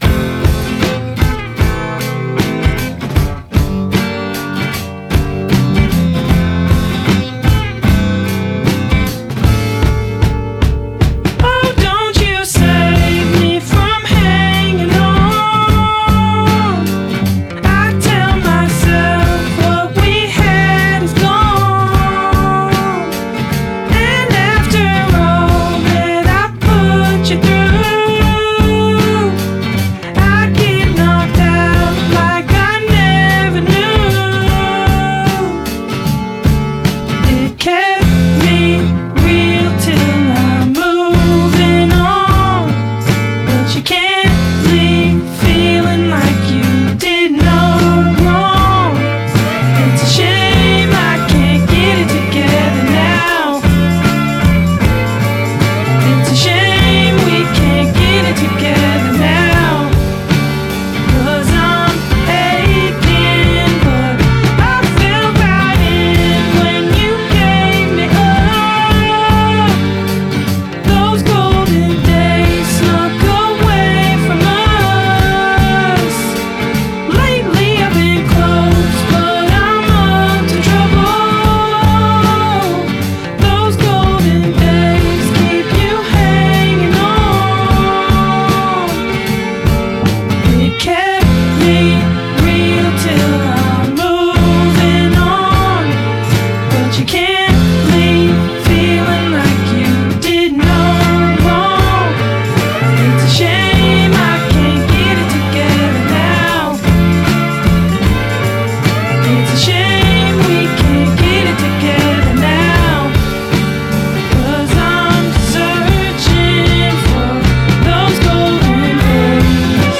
stands out with a 70s new wave groove.